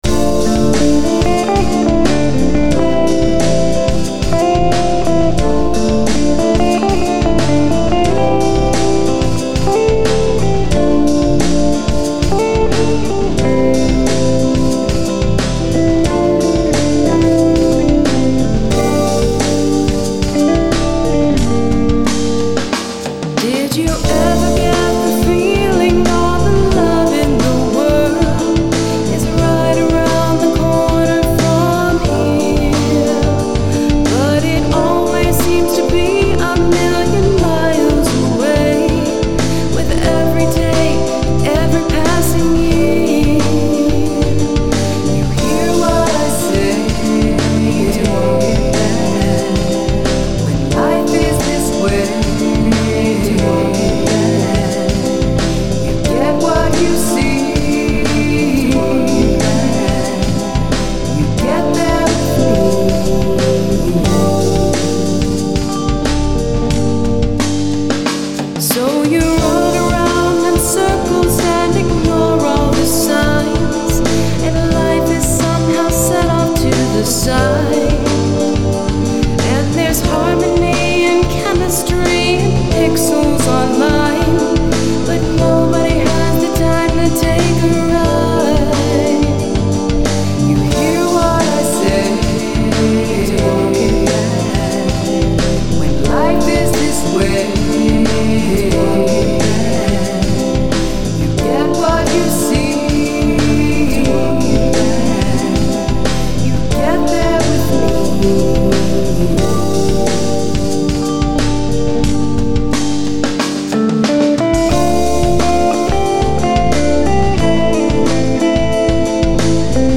guitars, basses, keyboards, programming, vocals